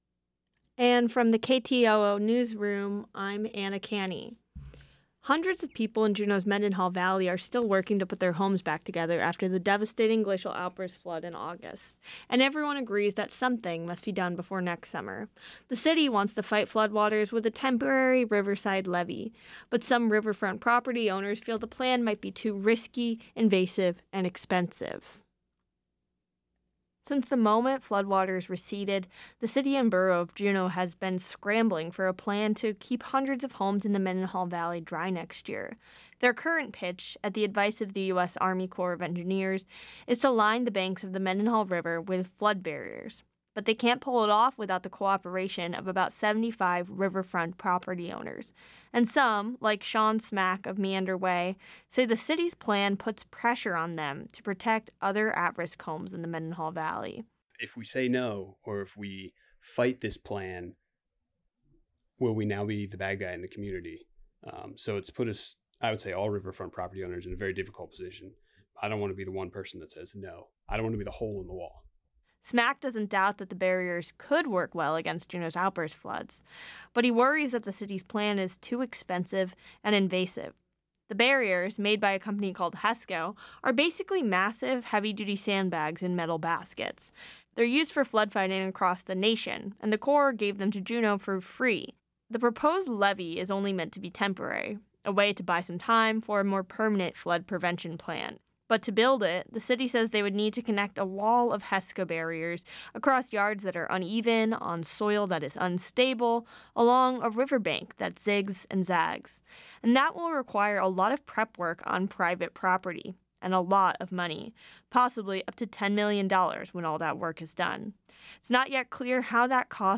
Newscast - Friday, Nov. 1, 2024